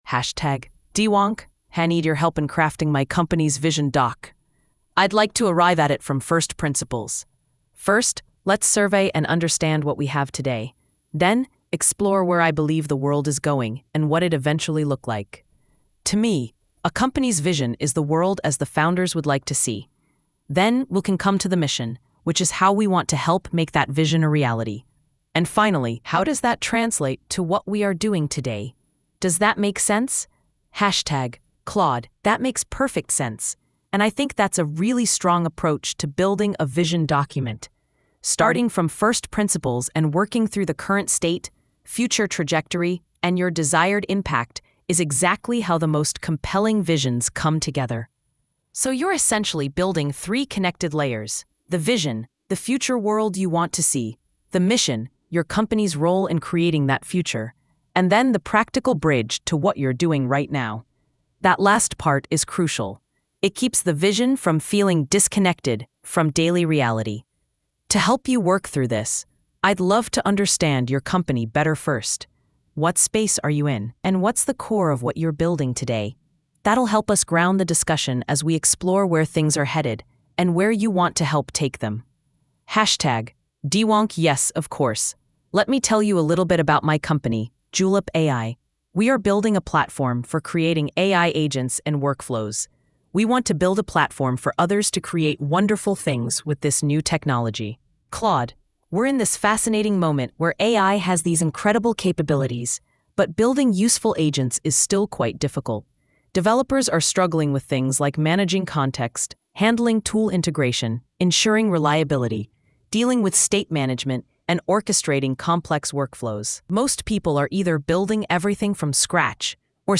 Audio: This post has audio recordings generated using gpt-4o-mini-speech placed at the beginning of each section.